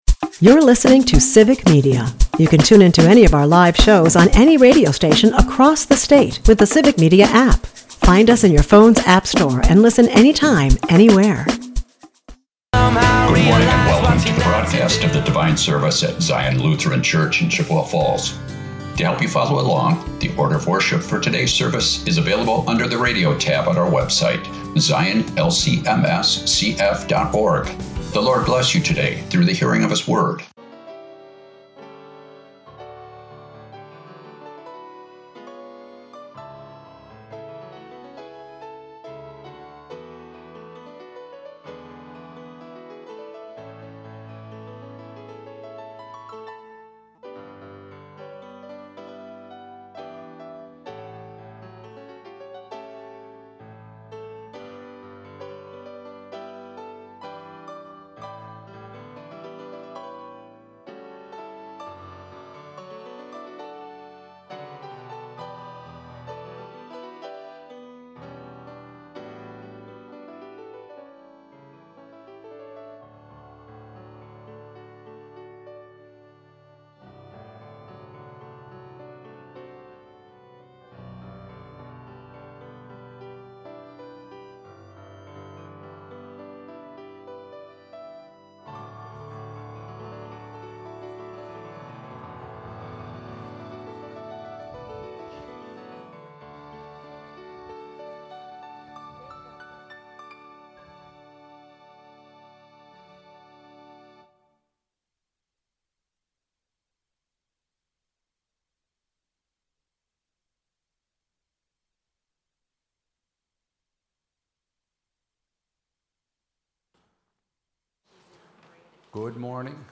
Sunday Service - Civic Media